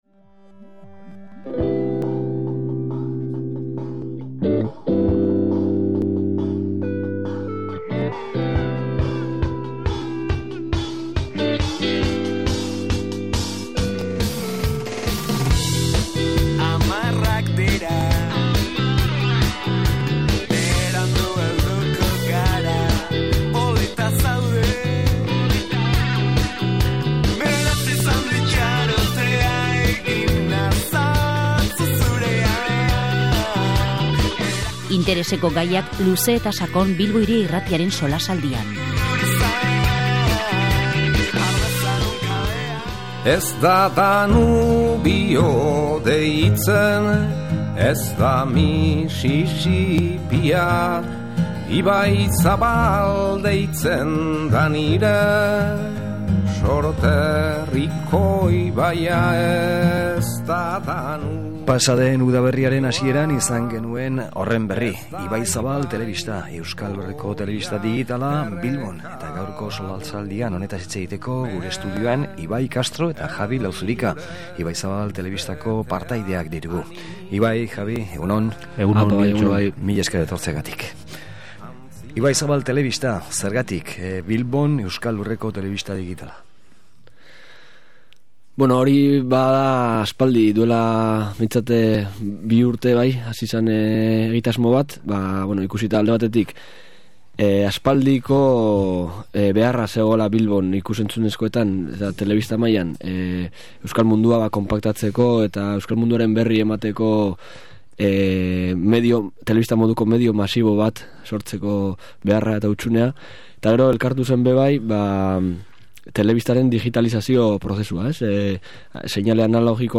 SOLASALDIA: Ibaizabal telebista